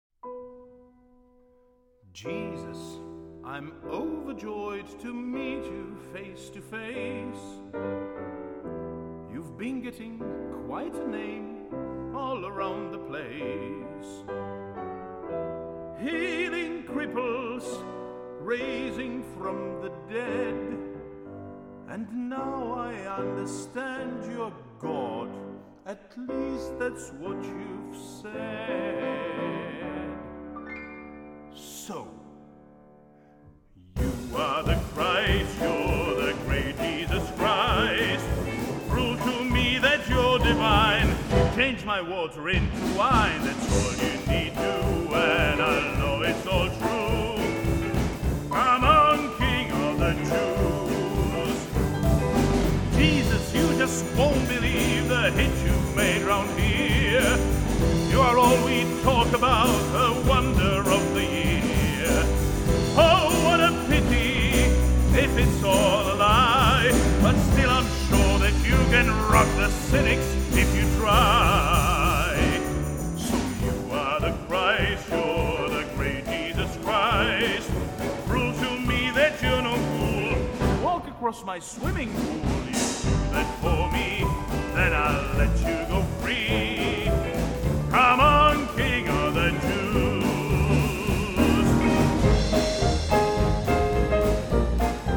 basbarytonisty
do světa muzikálů